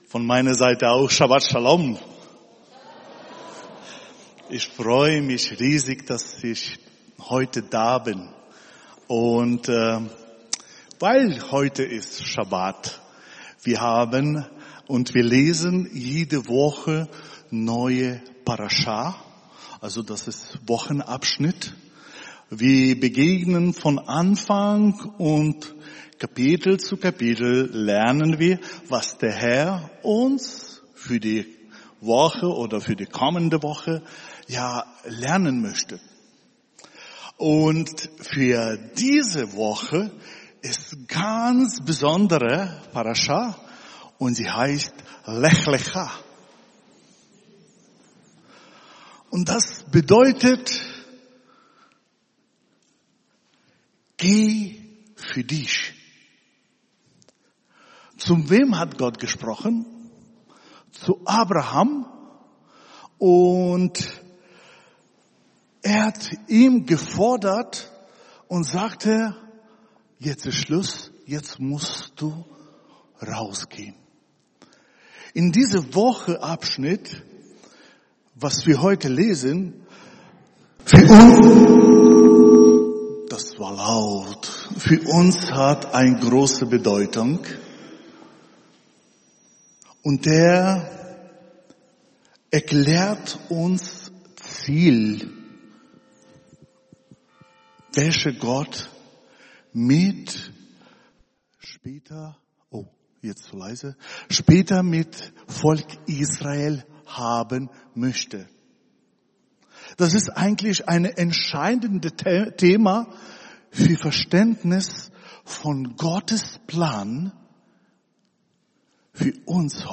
Messianischer Gottesdienst
November 2024 Gastsprecher https